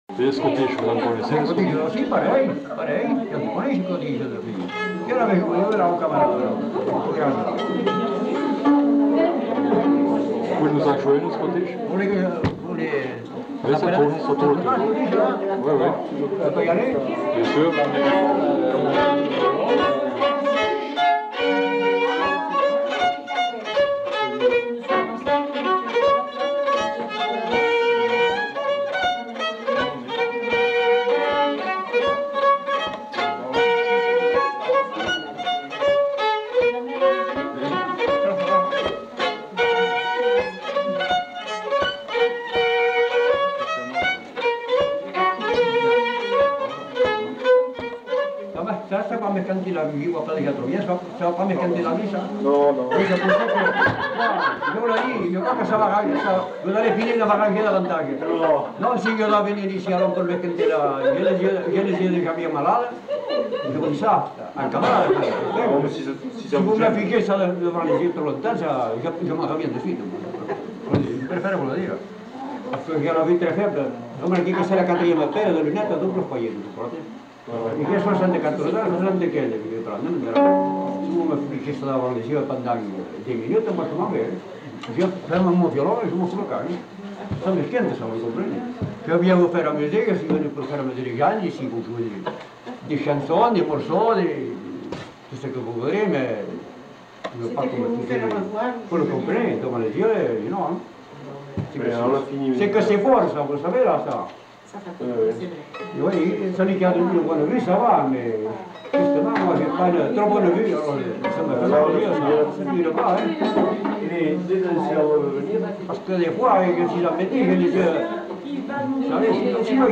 Lieu : Allons
Genre : morceau instrumental
Instrument de musique : violon
Danse : scottish